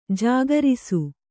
♪ jāgarisu